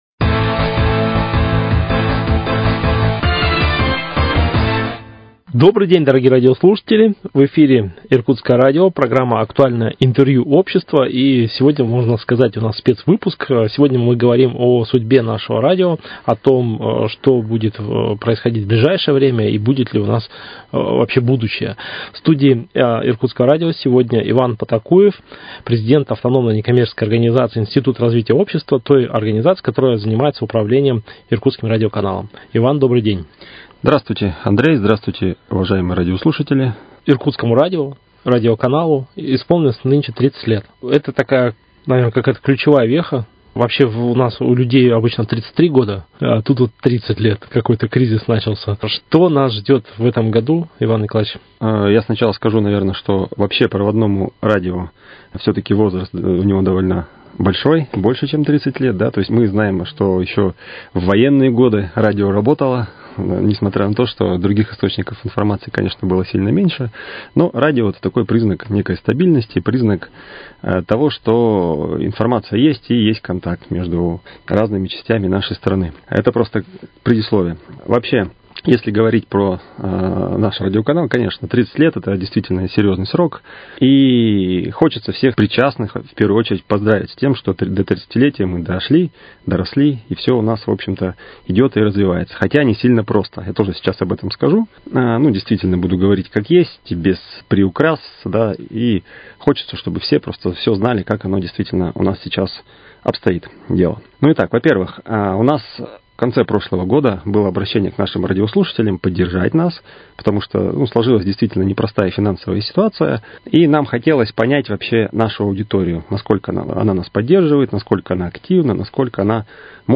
Актуальное интервью: О будущем проводного радио